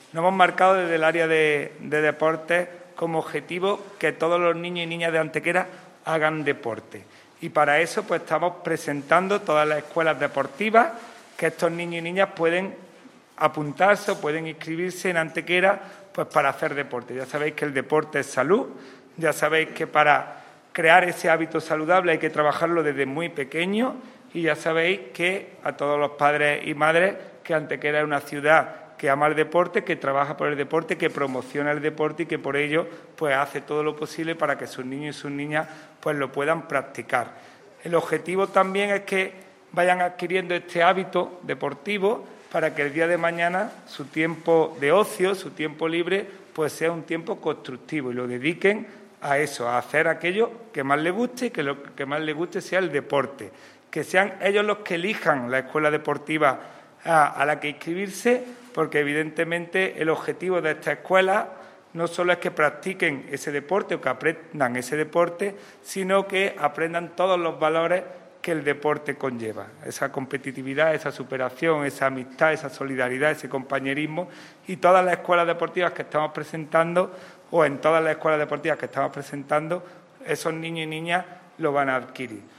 El teniente de alcalde delegado de Deportes, Juan Rosas, ha presentado en rueda de prensa la puesta en marcha de otras dos escuelas deportivas municipales de cara a la nueva temporada deportiva 2021-2022.
Cortes de voz